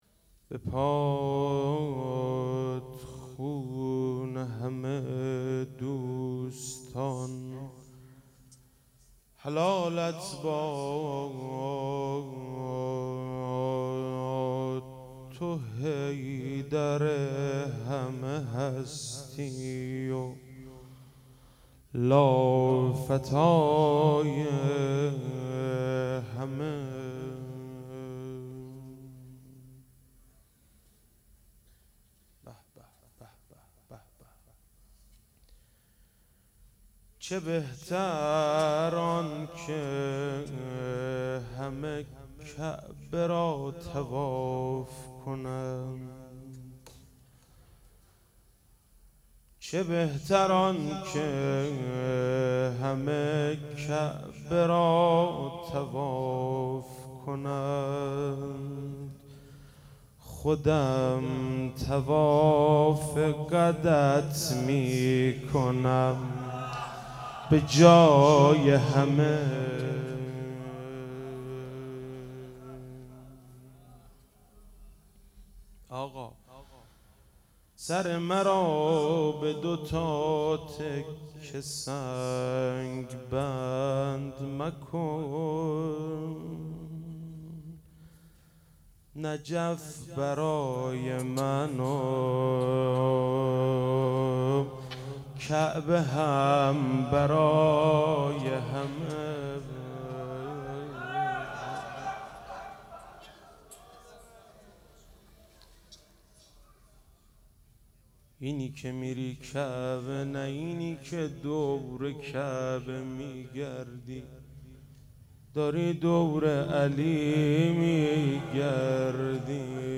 ماه رمضان
مدح